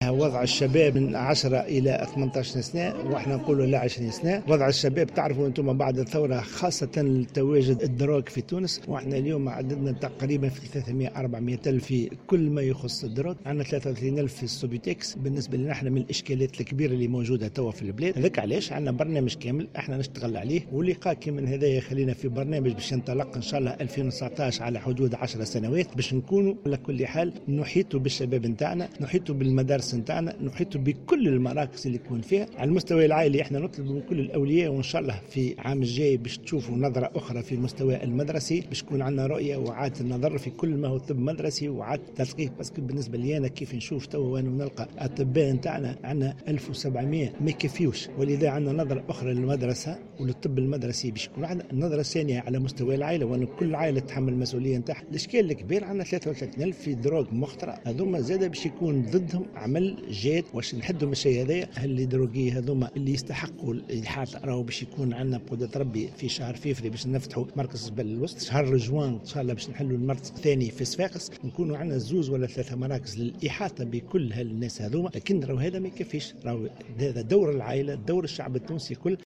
وأكد في تصريح لمراسلة الجوهرة اف ام، أن الوزارة تعمل على برنامج كامل سينطلق في 2019 على امتداد 10 سنوات، للإحاطة بالشباب والتلاميذ.